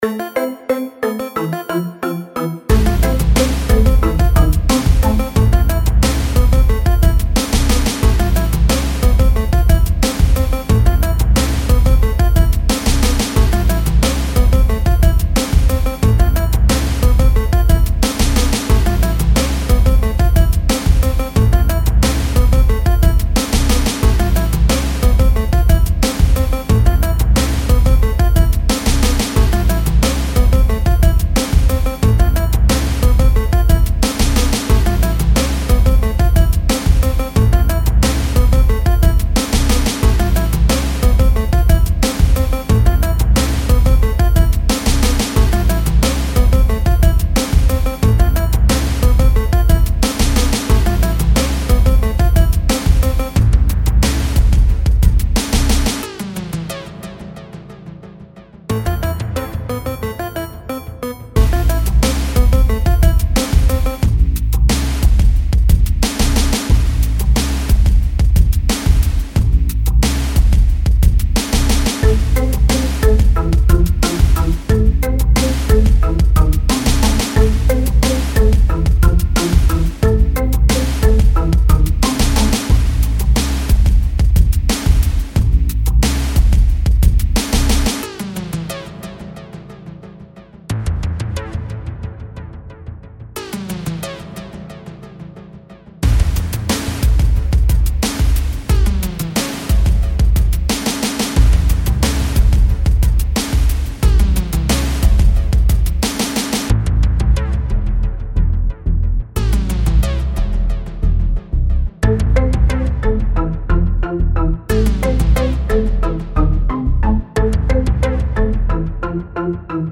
Žánr: Electro/Dance
Elektronika se snoubí s klasickou hudbou